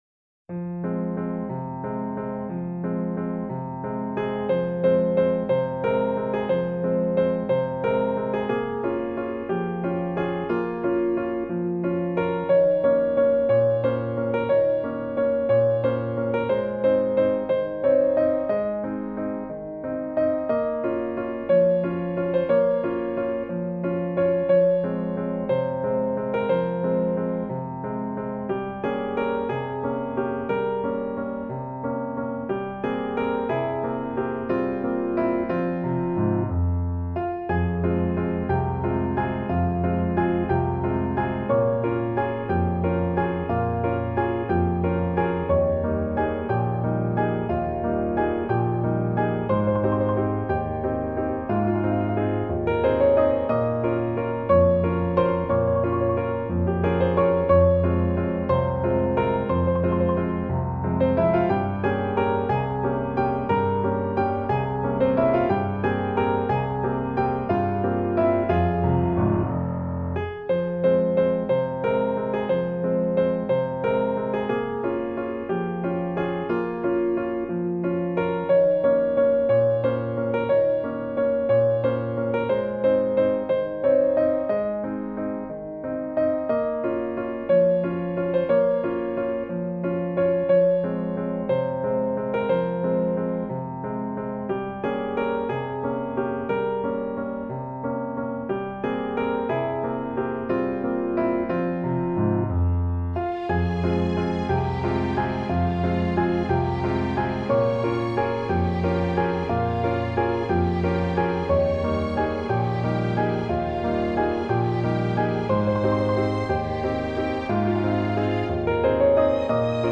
Instrumentation : Piano
Genre:  Valse Classique